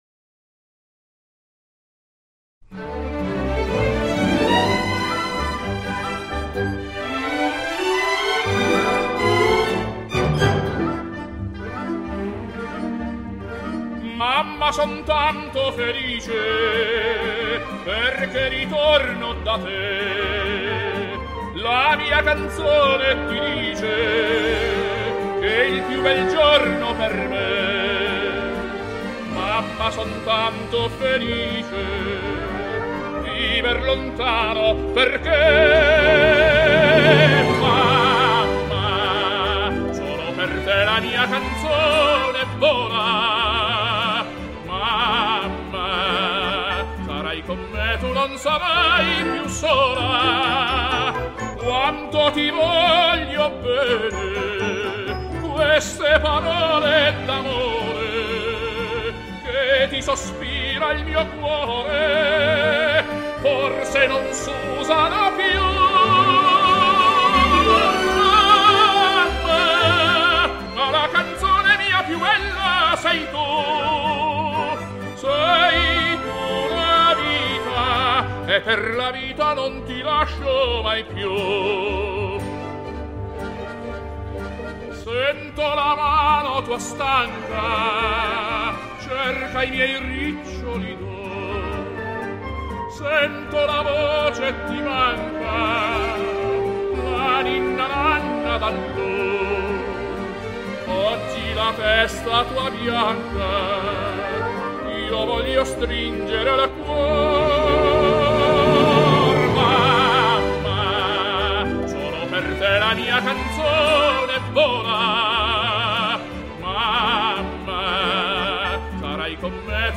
Classical, Opera, Pop